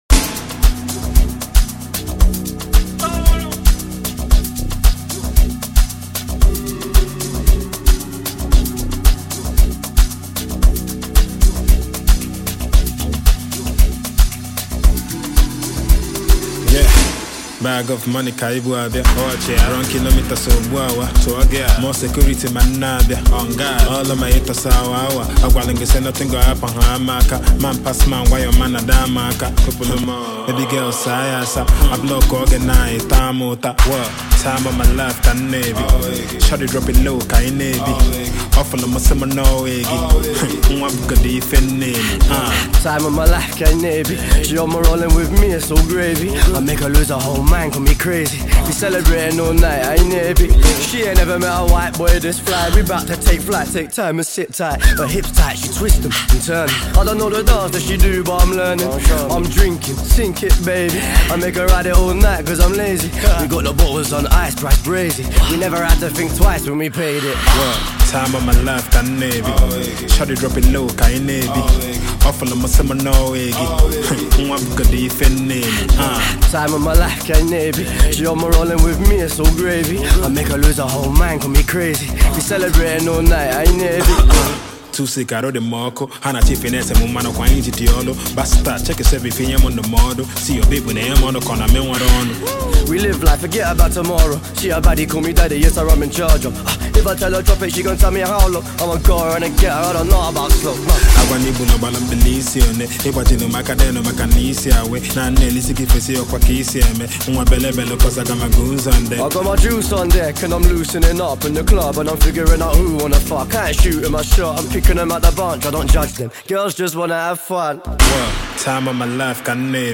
enchanting banger